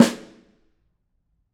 Snare2-HitSN_v7_rr1_Sum.wav